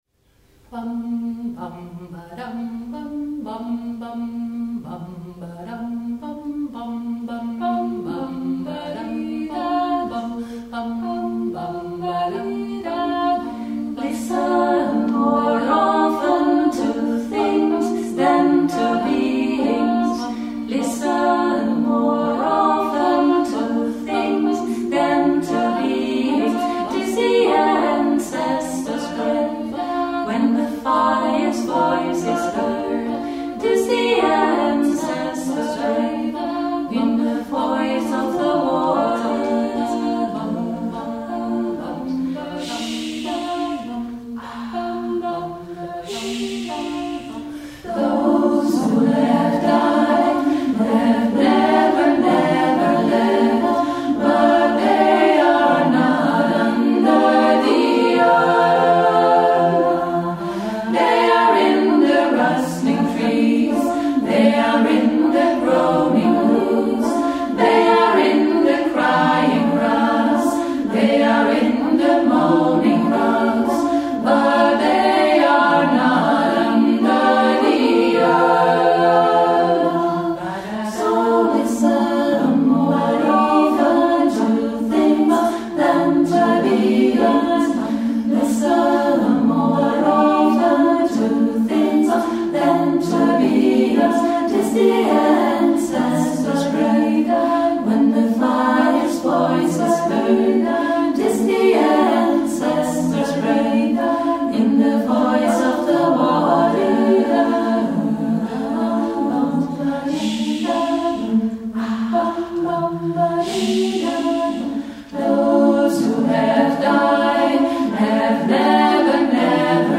Arrangementen voor koor.